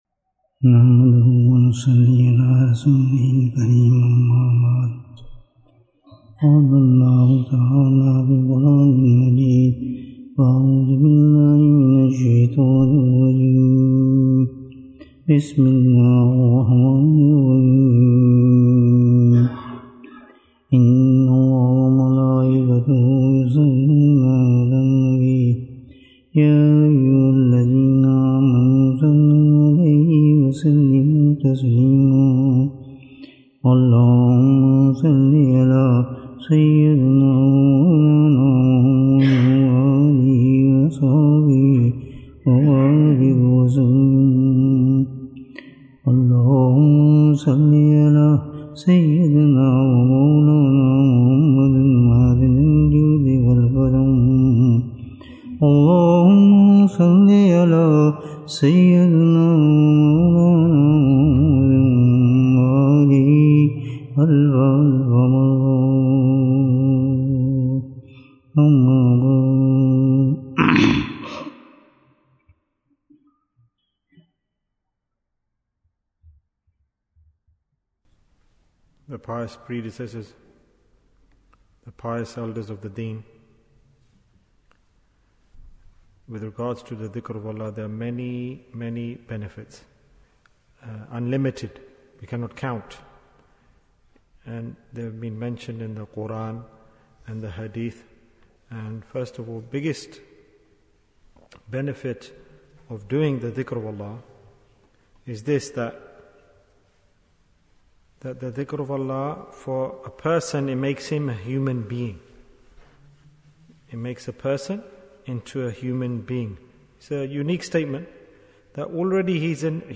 Who is a Human Being? Bayan, 34 minutes25th May, 2023